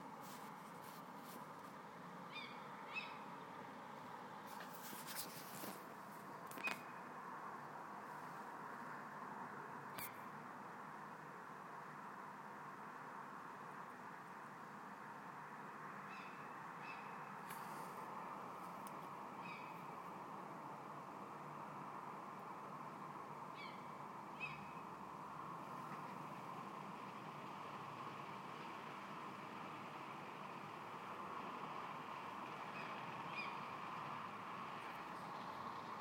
owl? what kind?